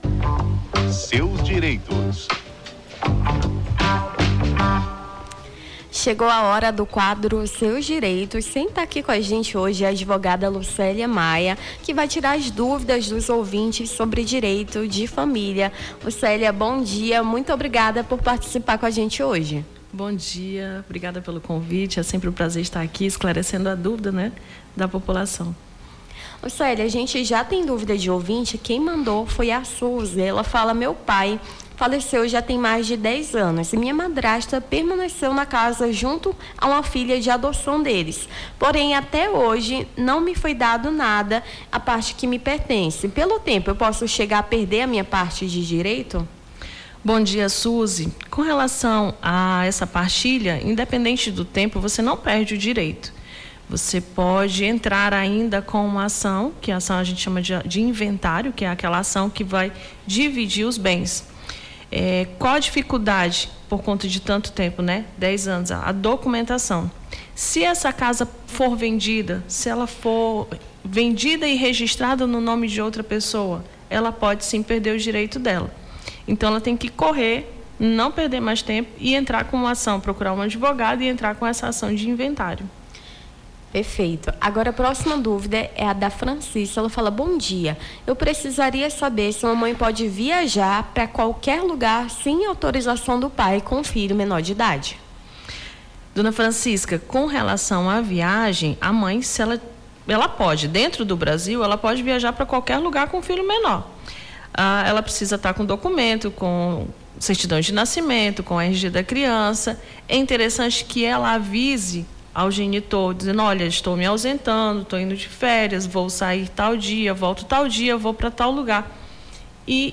Seus Direitos: advogada esclarece dúvidas dos ouvintes sobre direito de família